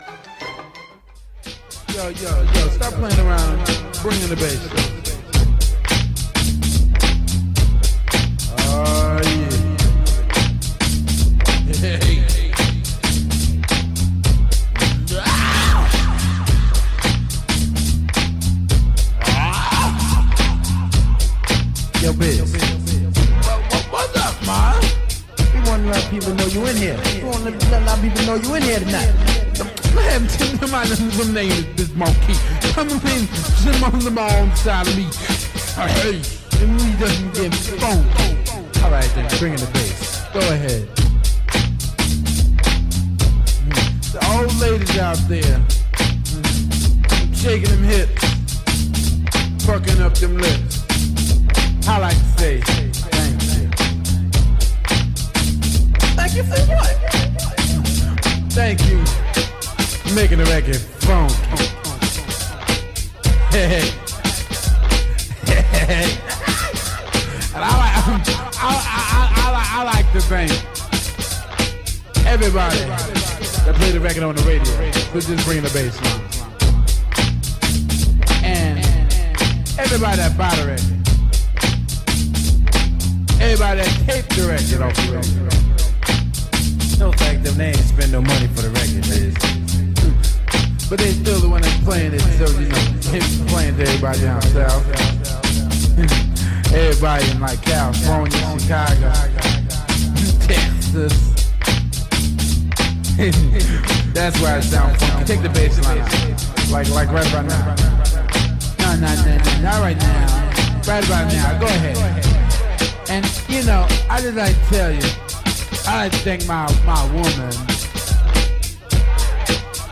He felt no need to make anyone laugh or think so he played music just to get thru today's mundane existence. blah, blah blah...